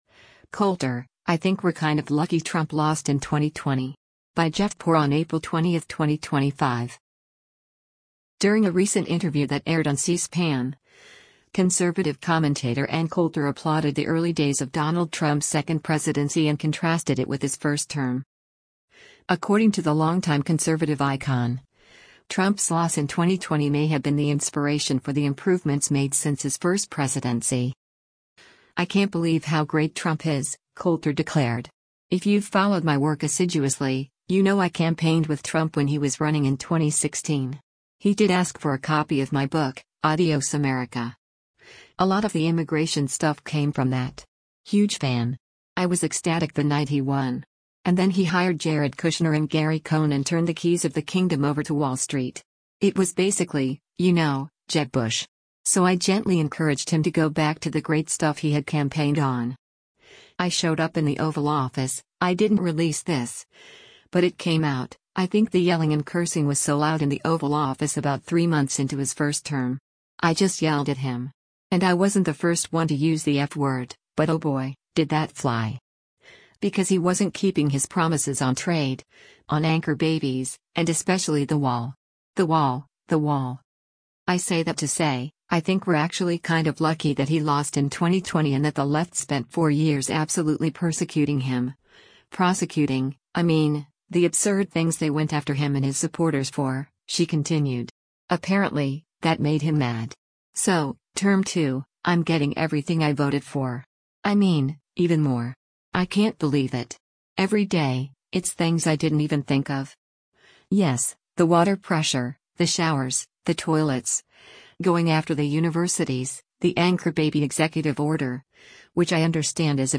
During a recent interview that aired on CSPAN, conservative commentator Ann Coulter applauded the early days of Donald Trump’s second presidency and contrasted it with his first term.